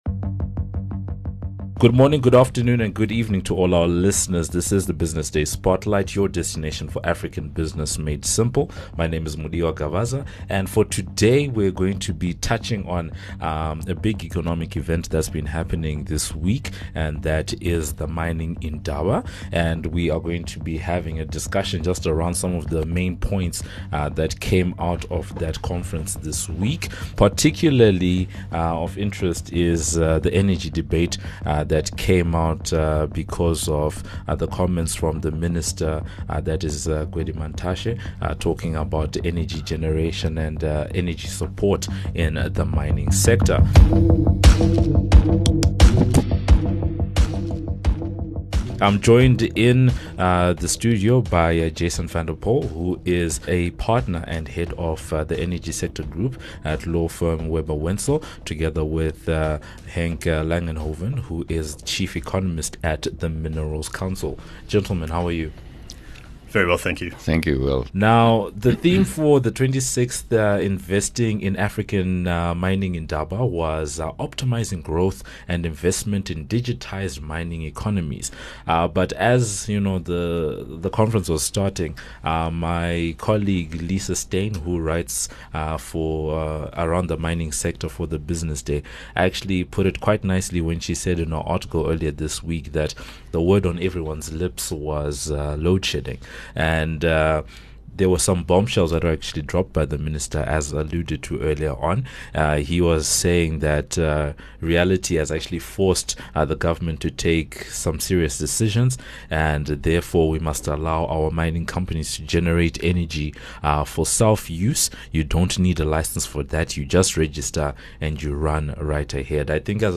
The discussion explores policy and economic implications of such a move would be for miners and what government still needs to do for the plan to be implemented, before shifting to highlight some of the other topical issues that came out of the indaba. Listen in to hear the full discussion and thoughts around these and other questions.